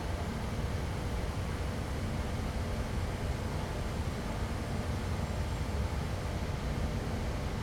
air_conditioner.L.wav